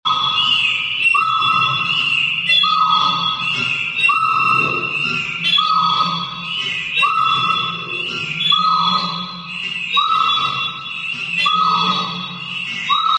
an experimental piece
The piece is created using several microphones that are suspended at varying lengths above a set of speakers and then pulled back and released. The different cable lengths cause the microphones to pass over the speakers at varying heights and speeds, which generates a chorus of feedback.